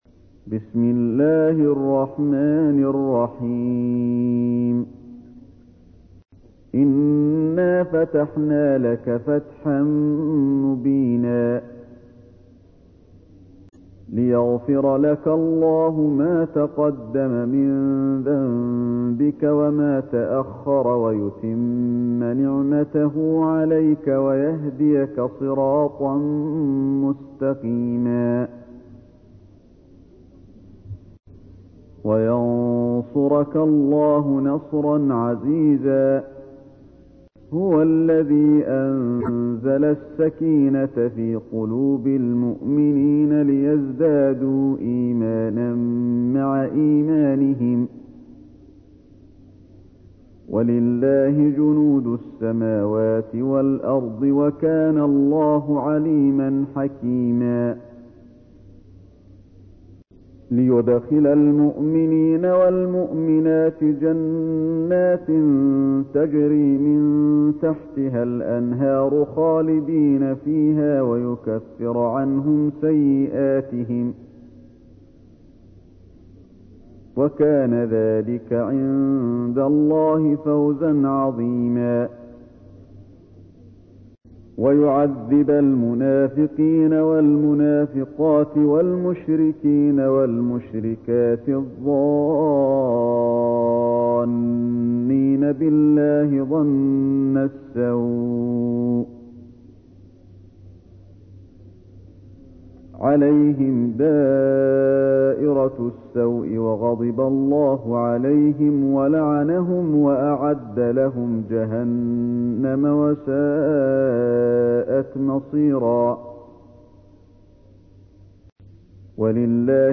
تحميل : 48. سورة الفتح / القارئ علي الحذيفي / القرآن الكريم / موقع يا حسين